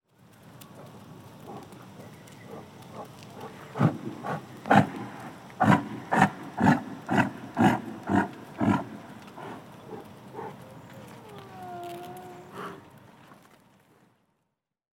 Шум дыхания яка на лугу